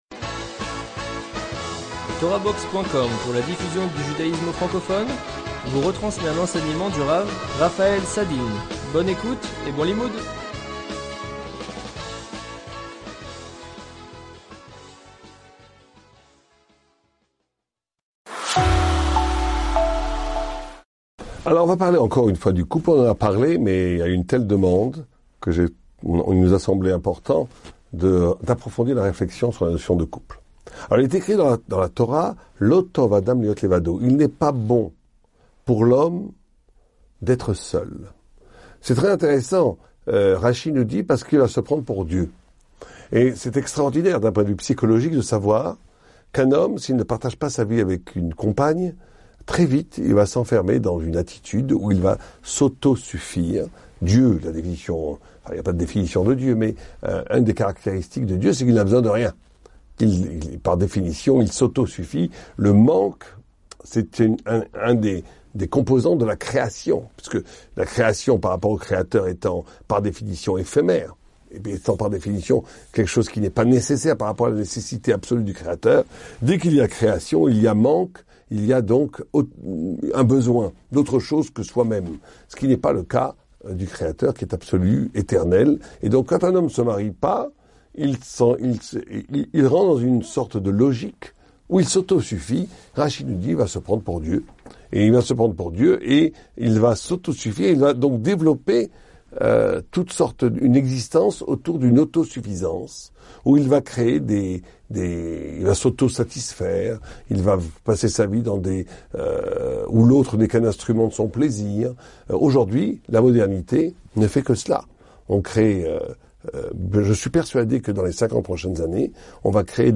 Cours vidéo